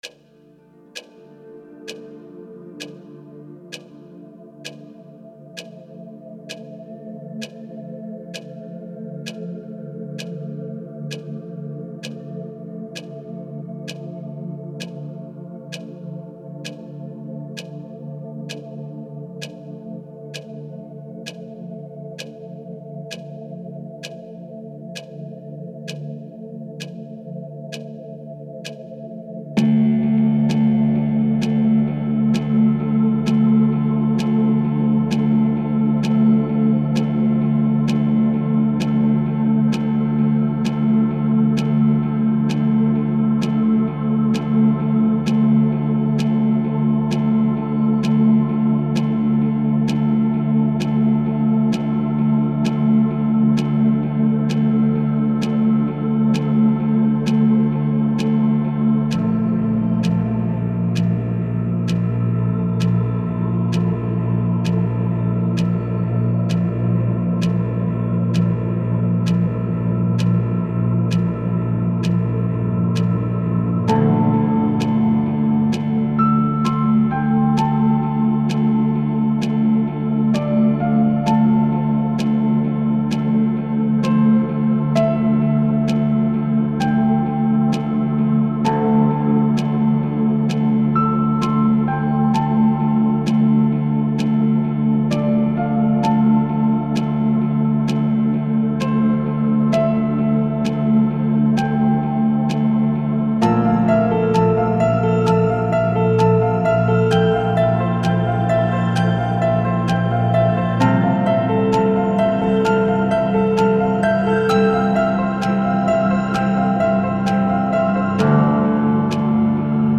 This piece relies heavily on NADA for the ambience to create a song of tension and extreme isolation.
ambient dark tension
Really cool sound texture you've created. I like the theme of the "echoes" surfacing and fading back.